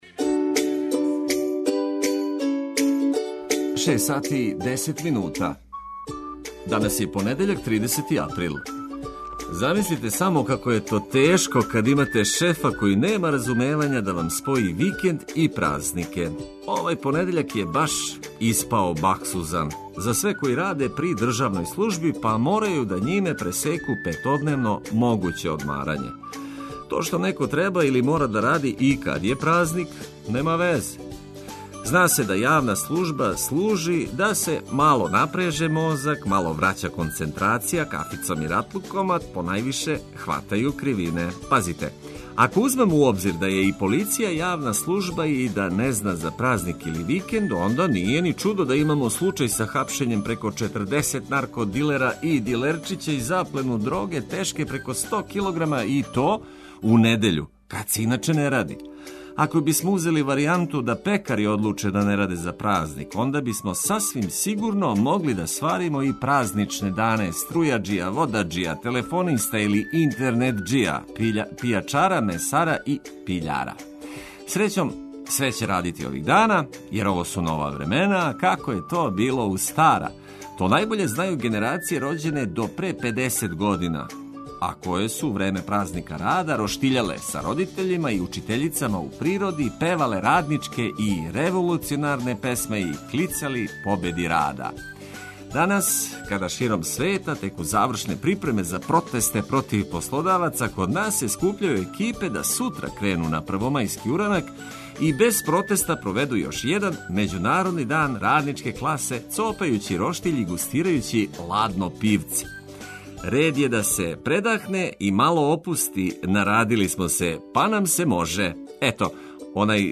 Буђење је пријатније уз ведру музику и важне информације.